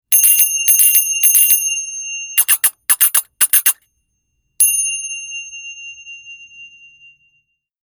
Produce a clear high pitched ring that is audible even in the largest orchestras. Matched for a pleasing blend of tones. 2-1/2" diameter.
Clear & Strong.